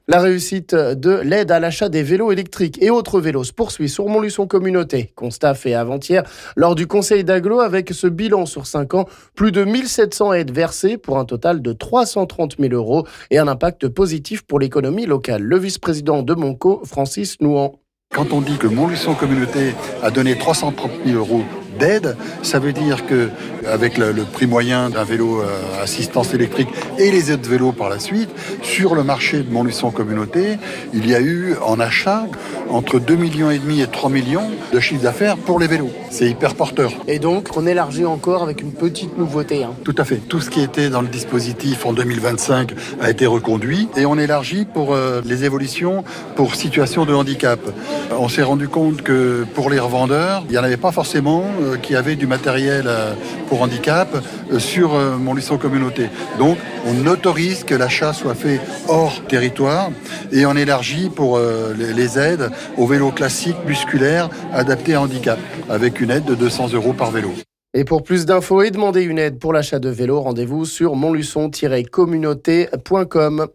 On en parle ici avec le vice-président de MonCo Francis Nouhant...